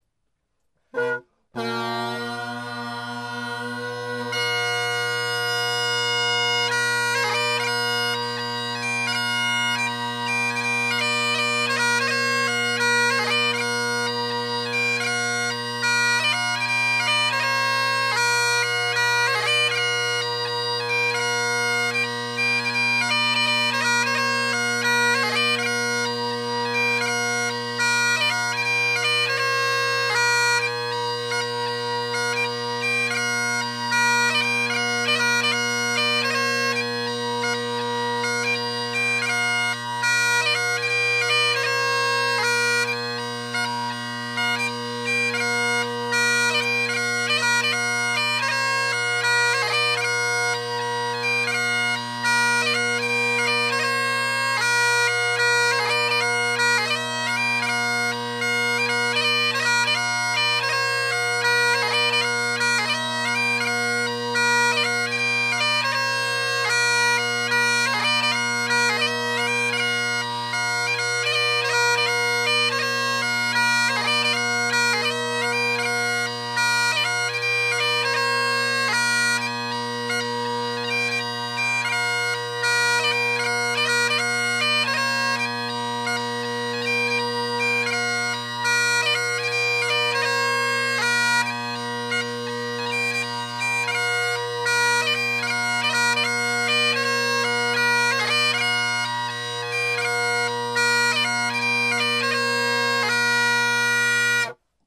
Drone Sounds of the GHB, Great Highland Bagpipe Solo
In the recordings below I set the high A just a tad flat as I’m lazy and taped the high G, F, and E. Note the C isn’t flat, even from the get go, which is cool.
The Sweet Maid of Mull – AyrFire chanter – MacLellan reed – MacPherson bagpipes – Kinnaird reeds